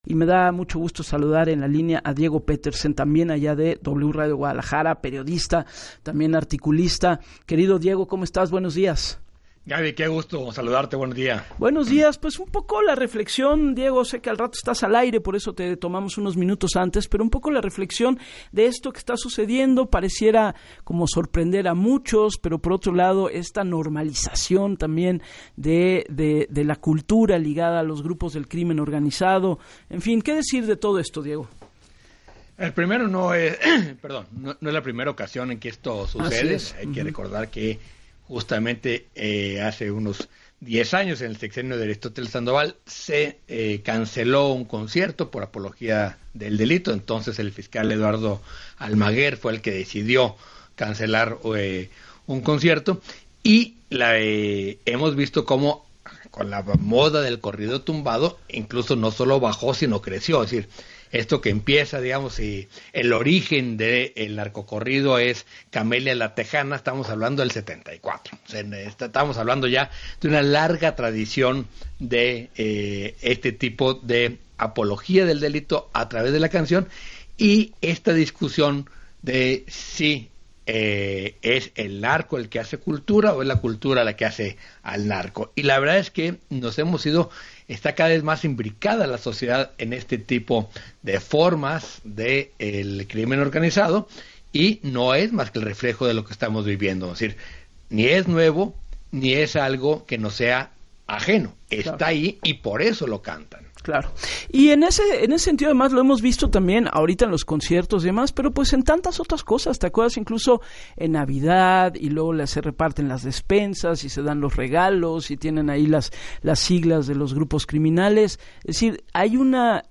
En entrevista para “Así las Cosas” con Gabriela Warkentin, aseguró que lo ocurrido en el concierto que homenaje a “El Mencho”, “no es la primera ocasión que sucede como apología del delito”, recordó “una larga tradición” del narco corrido con canciones como “Camelia la texana” y cómo el fiscal Eduardo Almaguer decidió cancelar un concierto, “pero la moda del corrido tumbado no solo no bajó, sino que creció”.